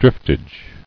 [drift·age]